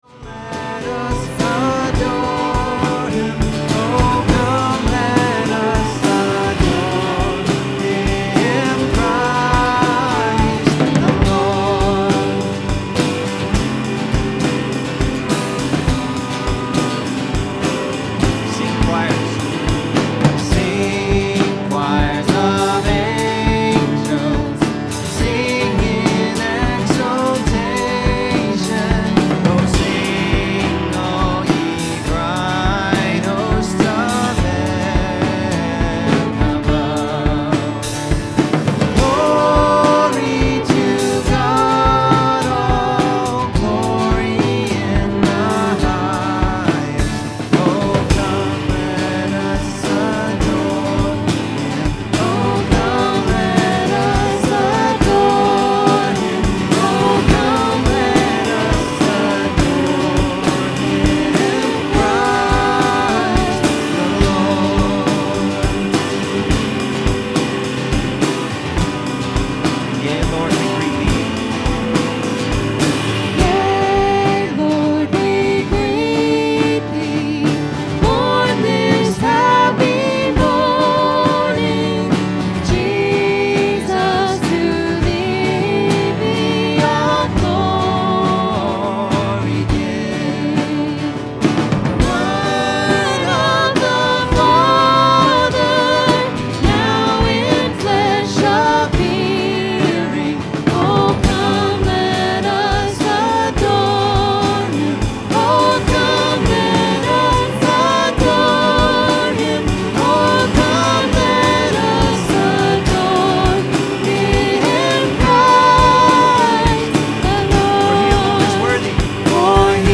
This sermon was originally preached on Sunday, December 23, 2025.